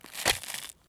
crafted.wav